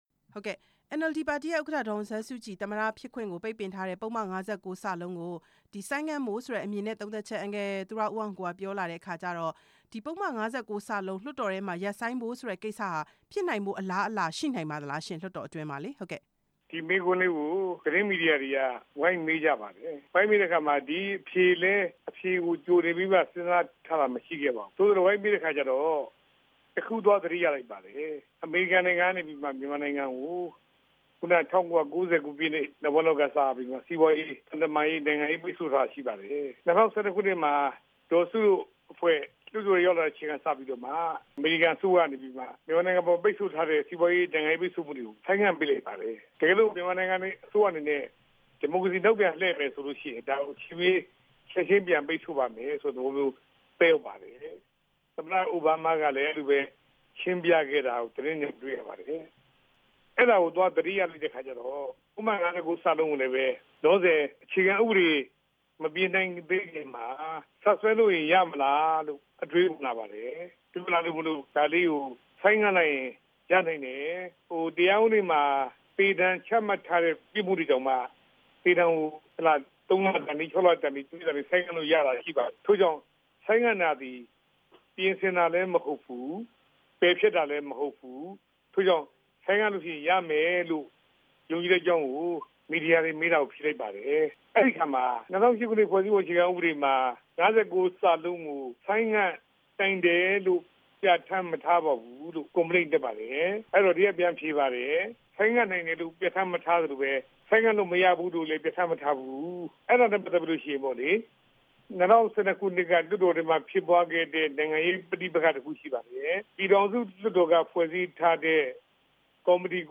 ပြည်သူ့လွှတ်တော် ကိုယ်စားလှယ် သူရဦးအောင်ကိုနဲ့ မေးမြန်းချက်